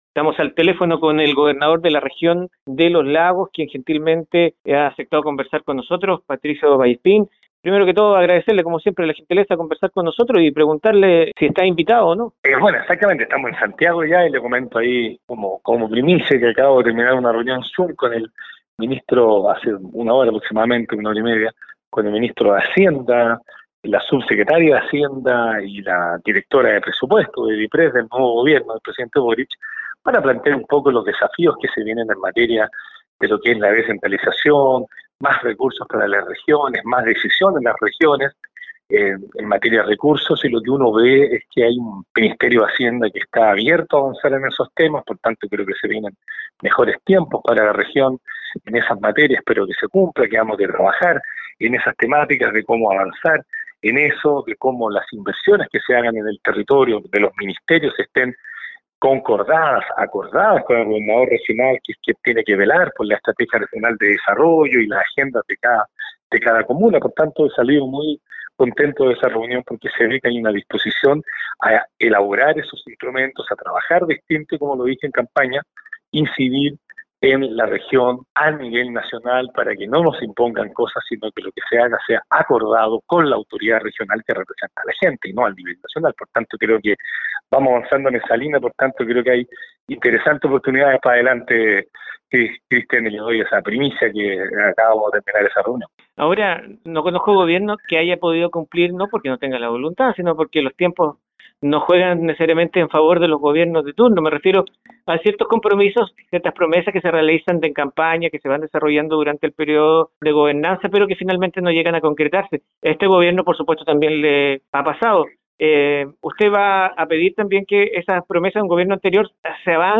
Conversamos con Patricio Vallespín, Gobernador de la Región de Los Lagos, que por estos días se encuentra en la Capital debido al cambio de mando, instancia que aprovecho para conversar con las nuevas autoridades.